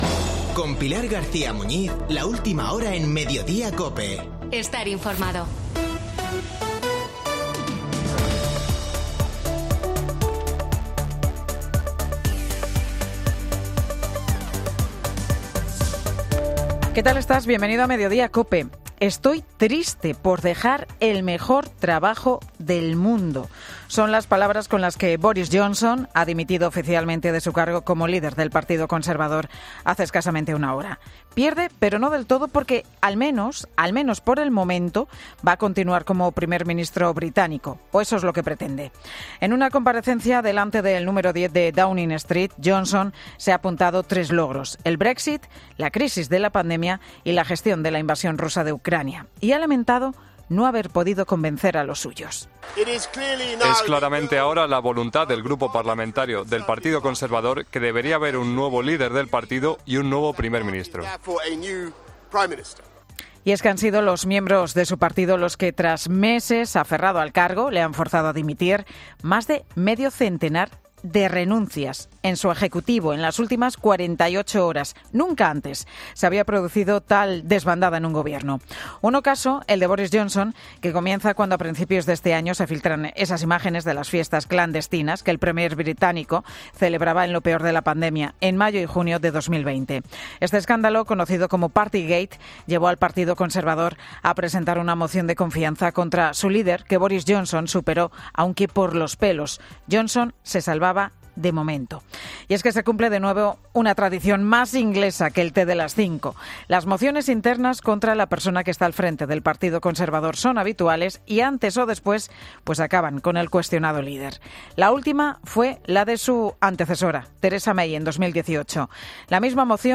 Monólogo de Pilar García Muñiz
El monólogo de Pilar García Muñiz, en 'Mediodía COPE'